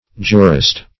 Jurist \Ju`rist\, n. [F. juriste, LL. jurista, fr. L. jus,